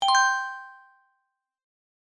決定ボタン04 - 音アリー